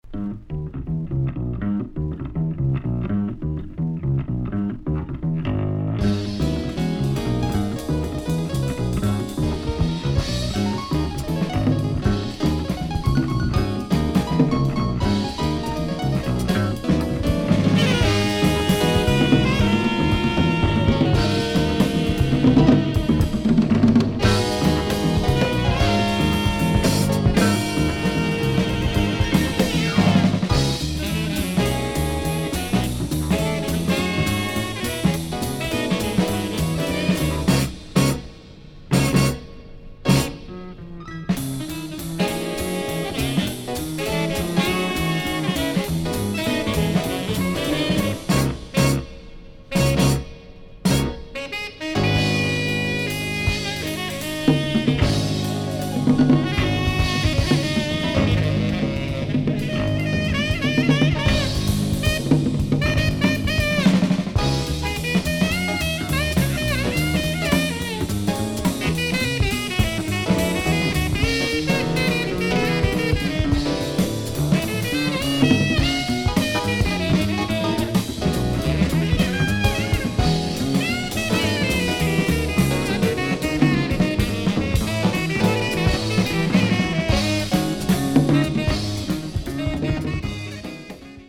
beautiful jazz from Galicia, in the North West of Spain.